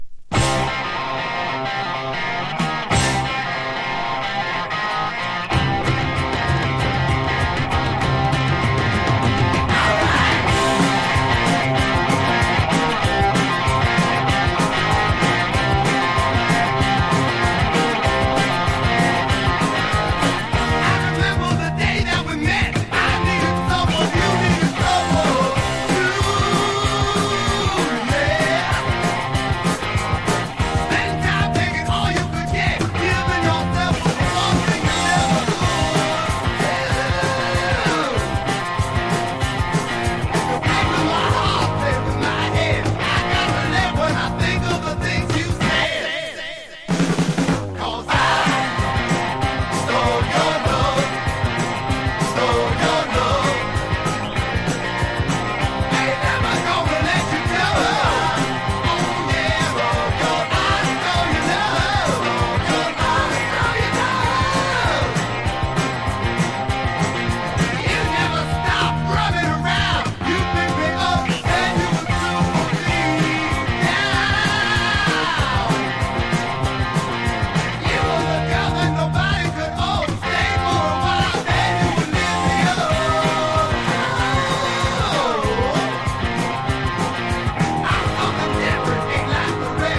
MIXTURE / LOUD / HR